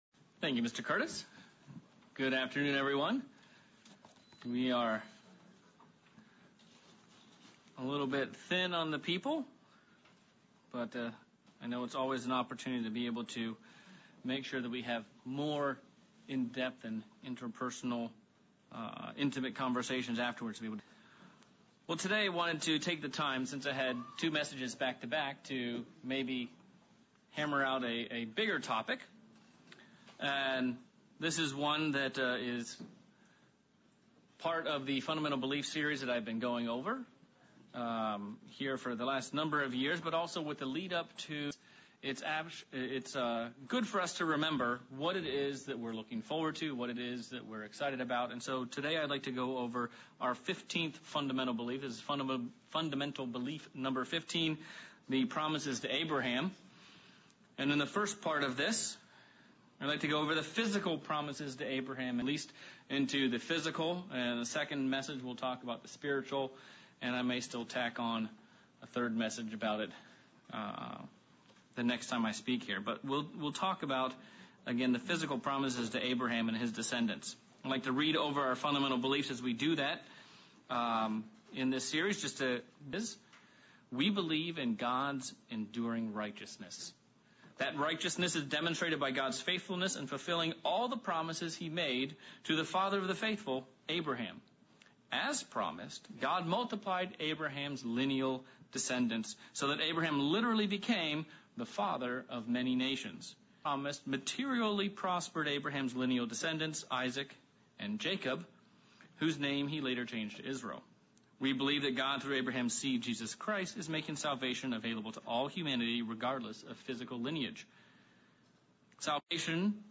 Sermon looking at our Fundamental belief #15 - The Physical promises and blessings to Abraham